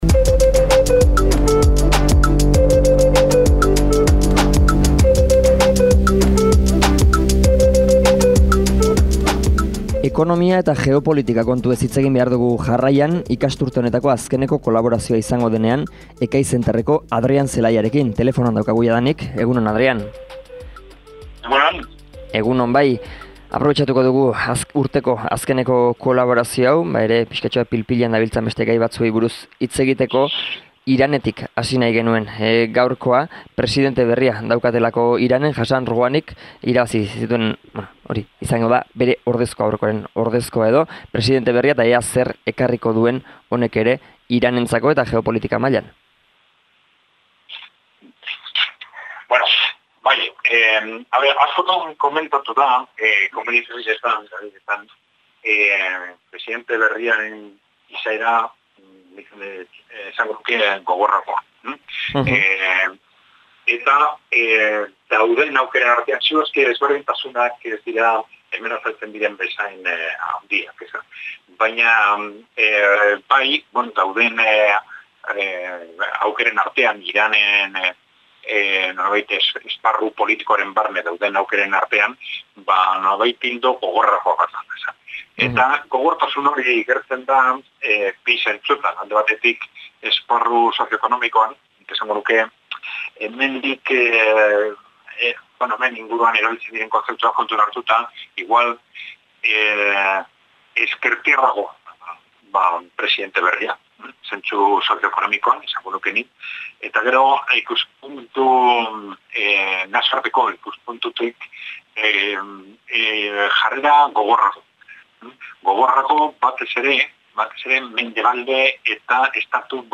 Elkarrizketa osoa hemen entzungai.